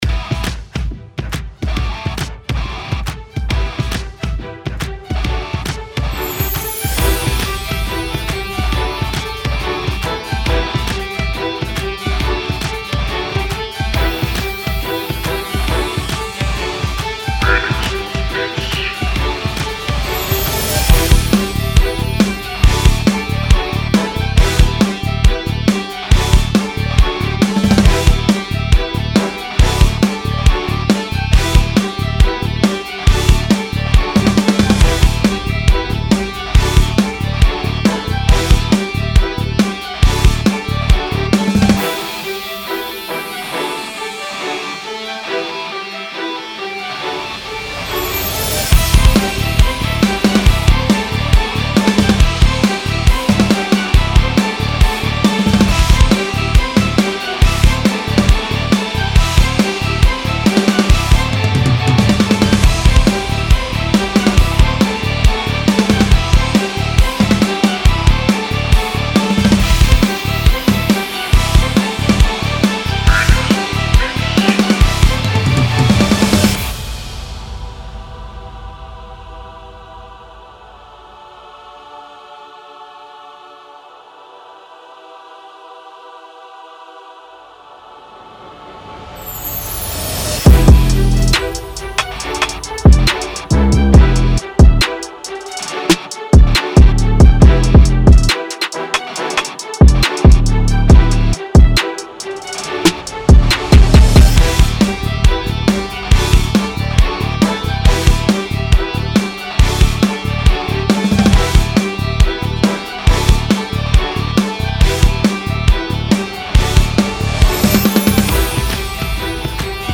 Epic Rap Instrumental